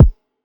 Work All Night Grind All Day Kick.wav